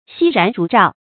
犀燃烛照 xī rán zhú zhào
犀燃烛照发音
成语注音 ㄒㄧ ㄖㄢˊ ㄓㄨˊ ㄓㄠˋ